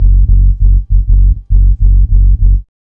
4508L B-LOOP.wav